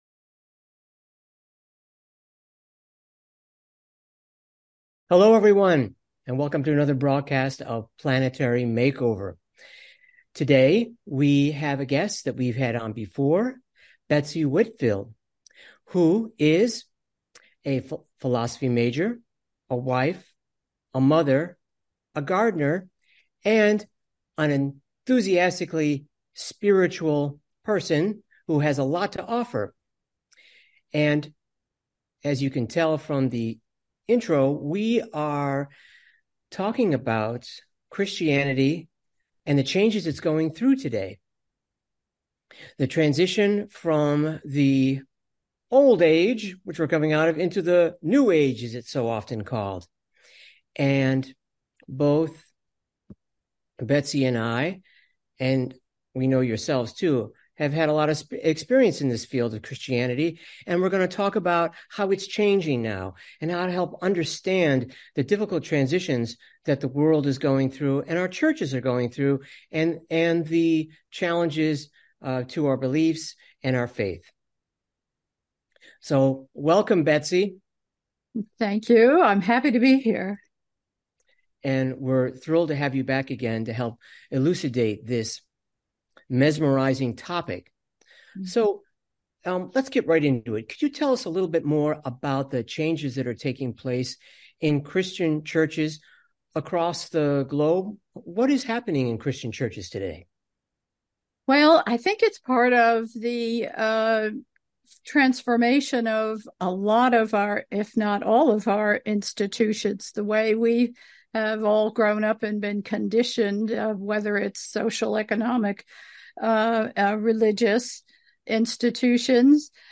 Talk Show Episode, Audio Podcast, Planetary MakeOver Show and Do You Feel the Re-Emergence of Deep Spirituality?